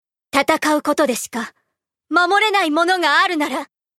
麗しの守り人 れんし 練 師 ＣＶ：神田 朱未 サンプルボイス コメント 孫尚香付の女官。
voice_renshi.mp3